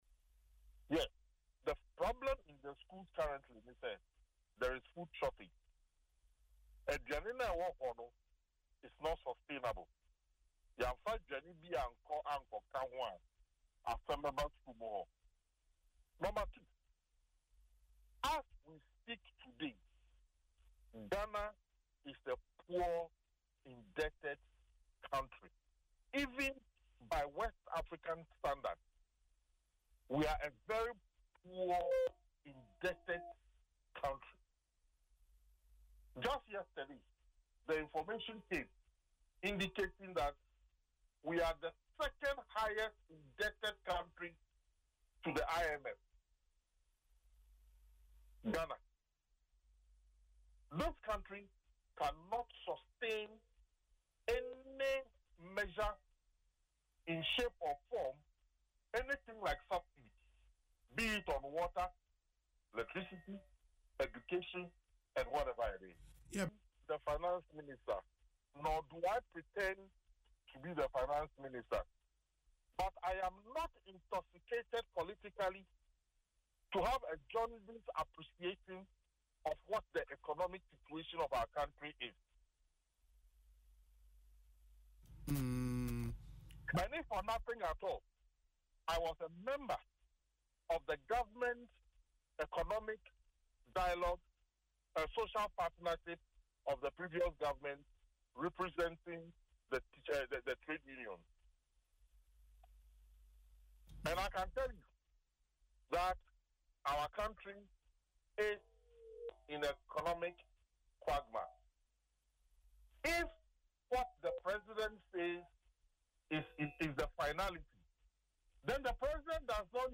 In an interview on Asempa FM’s Ekosii Sen, he highlighted the current food shortages in some senior high schools, warning that a lack of restocking could lead to serious problems.